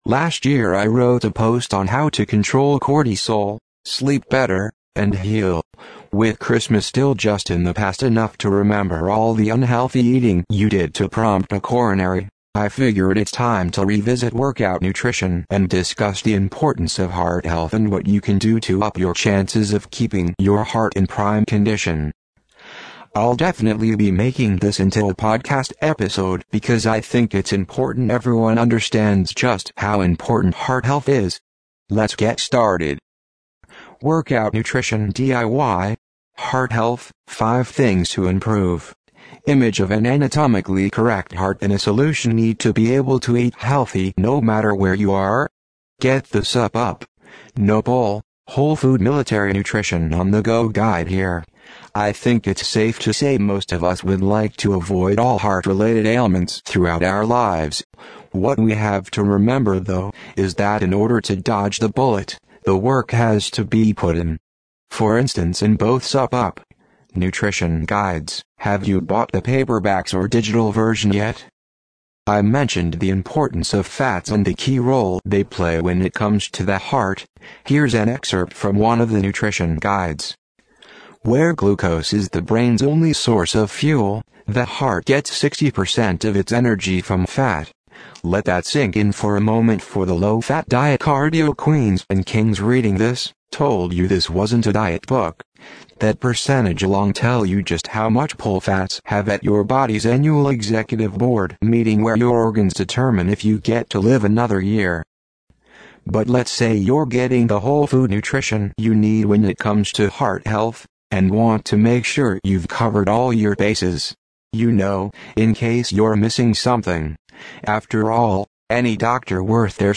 Workout-Nutrition-DIY-Heart-Health-5-Things-to-Improve-SUPP-UP-TTS-Audio.mp3